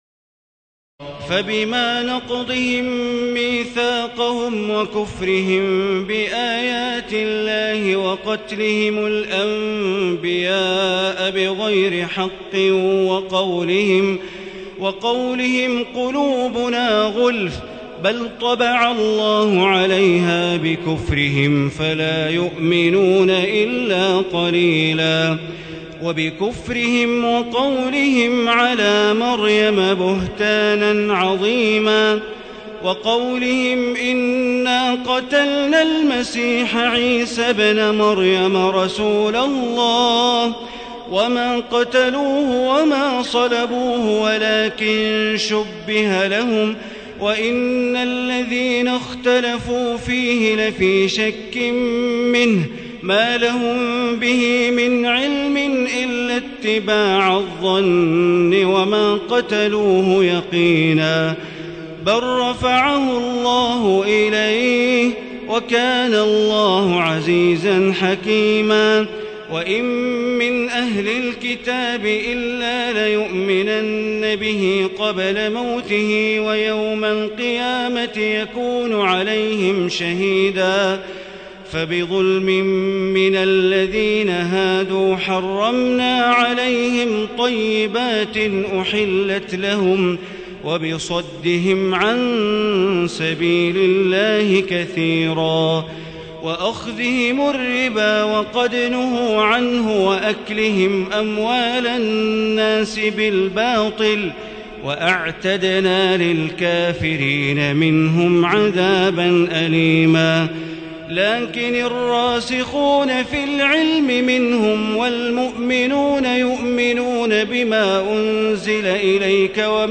تراويح الليلة الخامسة رمضان 1437هـ من سورتي النساء (155-176) و المائدة (1-40) Taraweeh 5 st night Ramadan 1437H from Surah An-Nisaa and AlMa'idah > تراويح الحرم المكي عام 1437 🕋 > التراويح - تلاوات الحرمين